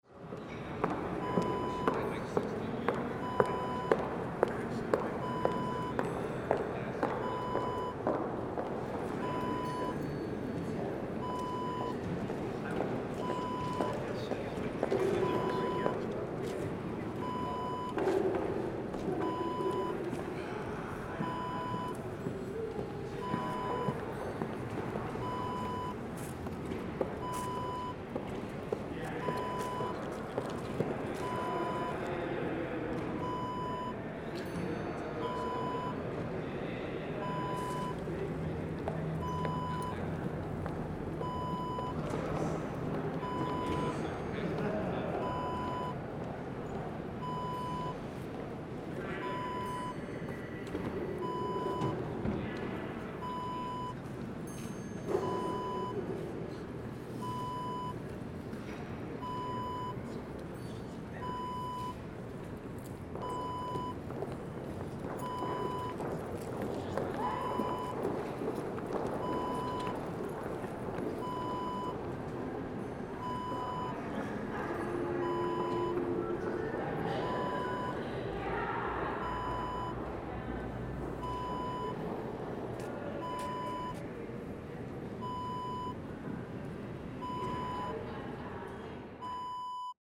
Business building lobby ambience sound effect .wav #2
Description: Business building lobby background ambience
Properties: 48.000 kHz 24-bit Stereo
A beep sound is embedded in the audio preview file but it is not present in the high resolution downloadable wav file.
Keywords: business, building, skyscraper, office, background, noise, ambience, high heels, people
business-building-lobby-ambience-preview-02.mp3